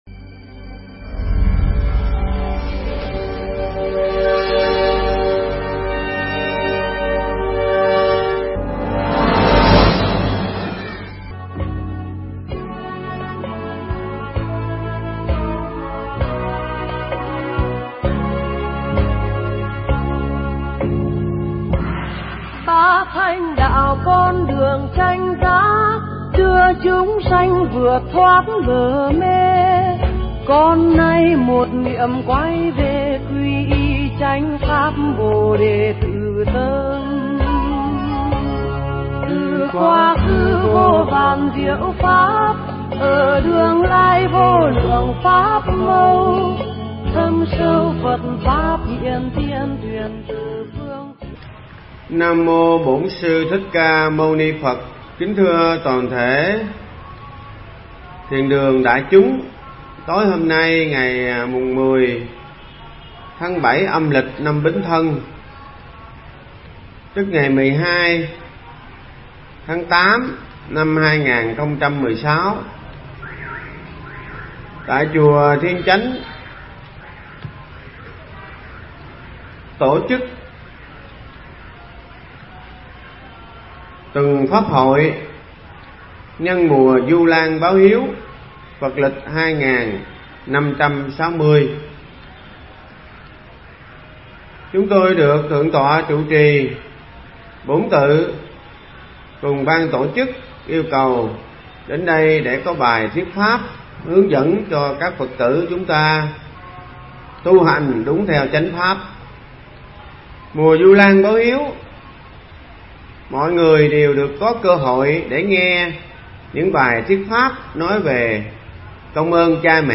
Nghe Mp3 thuyết pháp Bảy Tài Sản Không Bị Đánh Mất